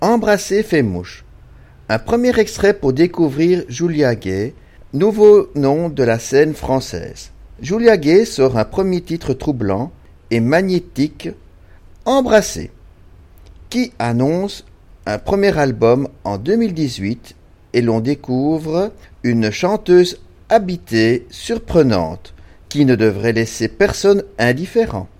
troublant et magnétique
une chanteuse habitée, surprenante
une grande voix est née!